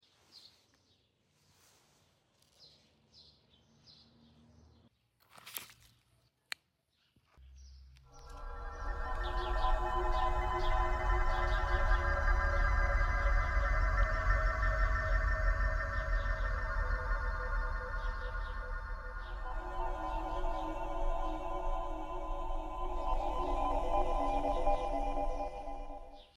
converte il suo bioritmo in musica
converts its biorhythm into music